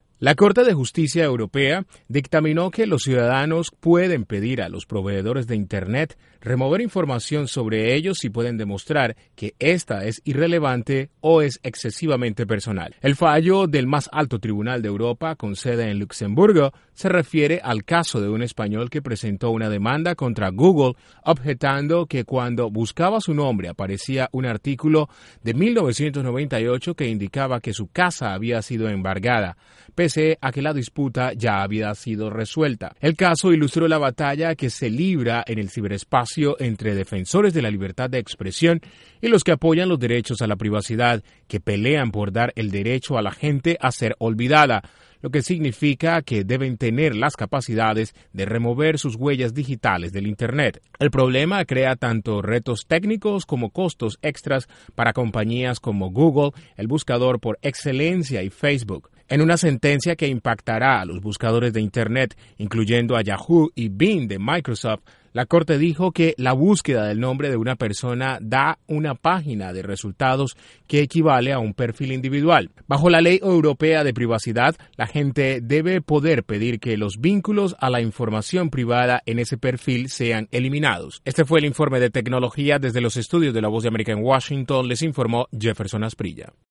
Los ciudadanos ahora pueden pedir a los proveedores de internet remover información sobre ellos si pueden demostrar que ésta es irrelevante o excesivamente personal. Desde la Voz de América en Washington informa